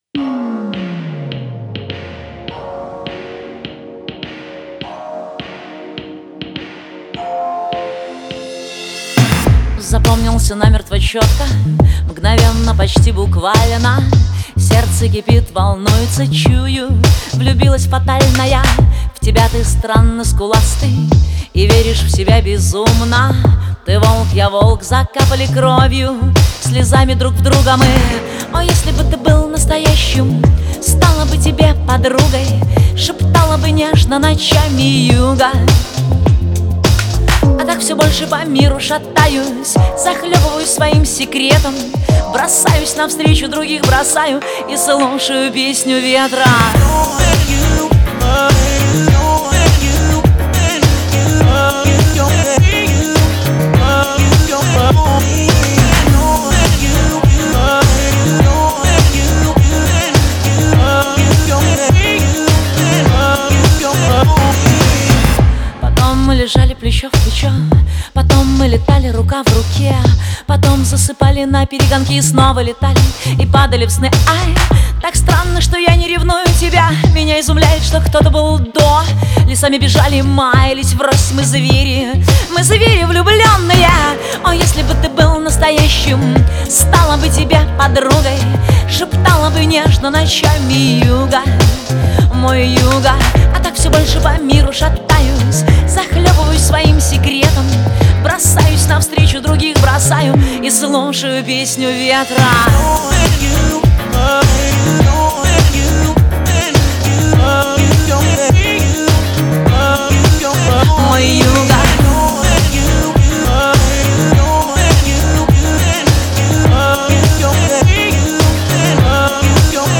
Категории: Русские песни, Рок.